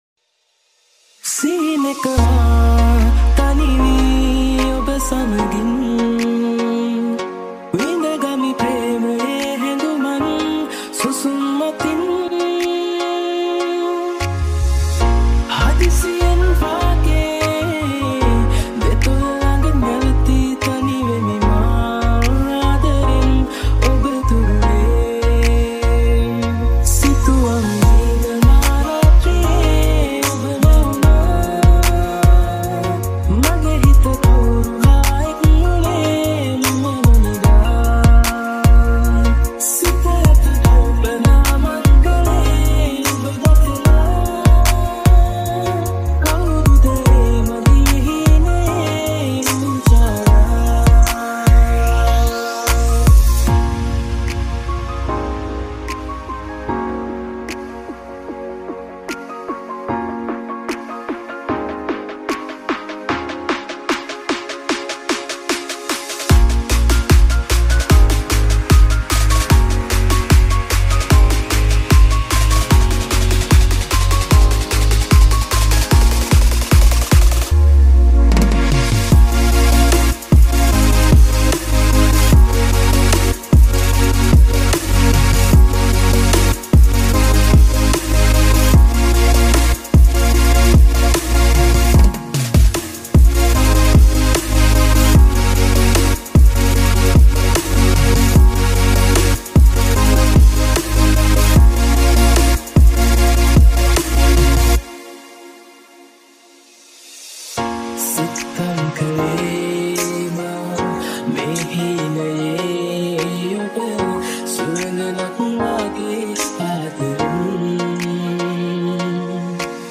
Future Bass Remix